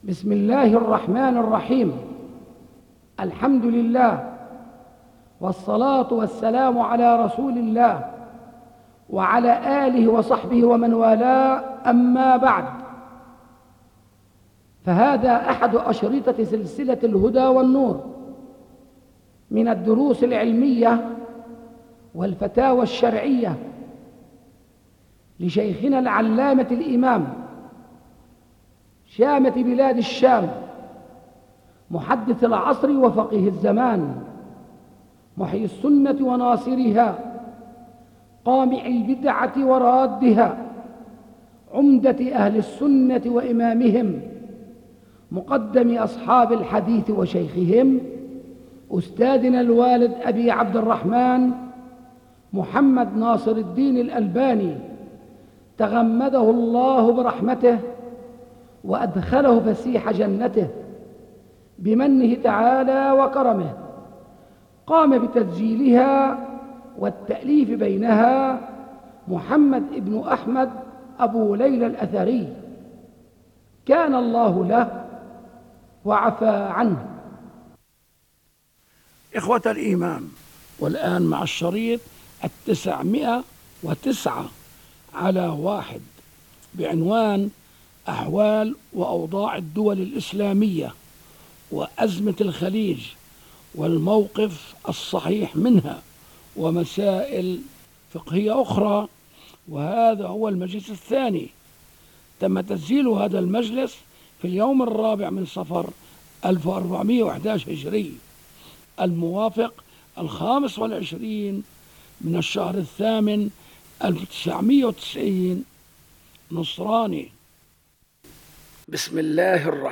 بِصوتِ الإِمامِ الألبَانِي